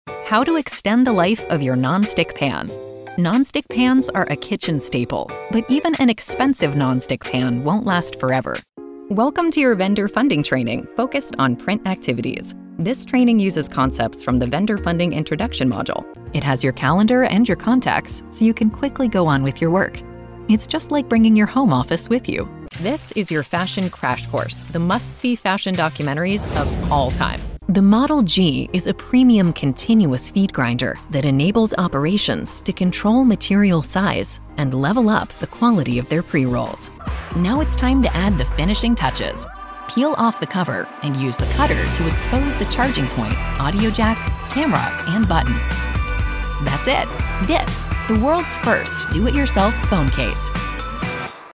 Female Voice Over Talent
Friendly, Warm, Conversational.
eLearning